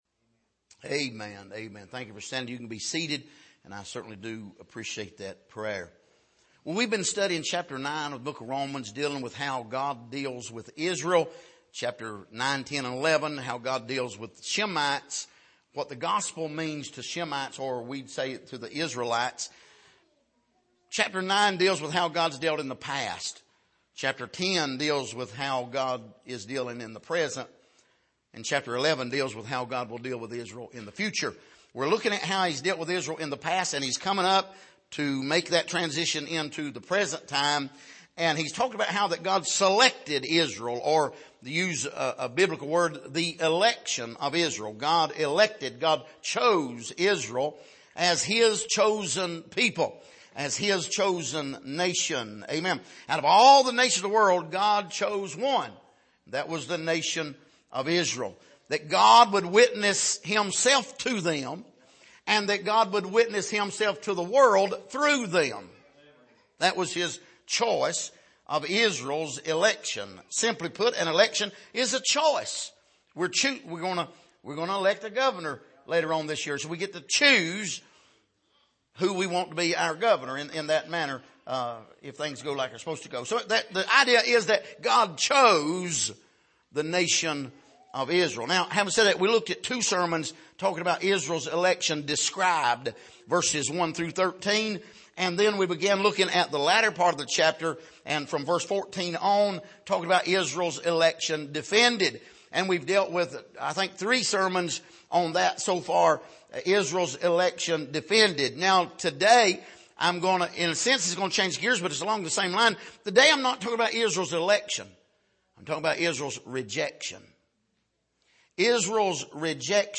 Passage: Romans 9:25-33 Service: Sunday Morning